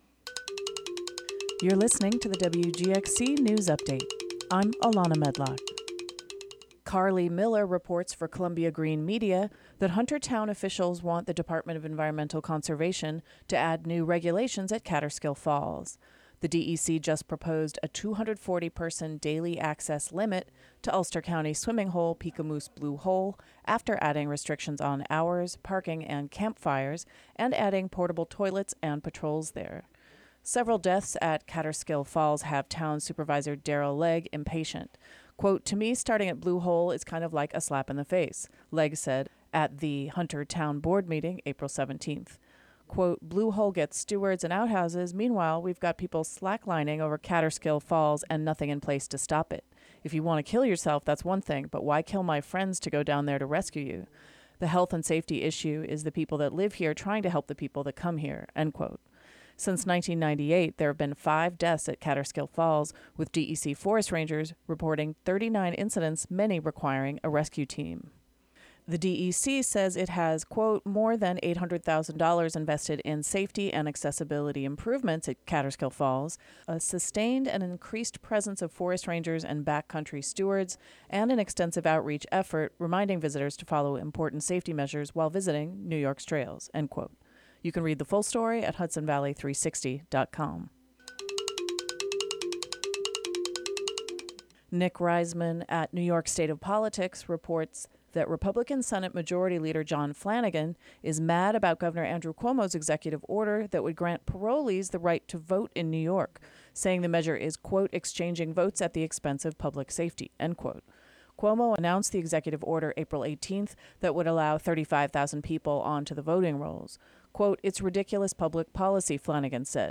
"All Together Now!" is a daily news show brought t...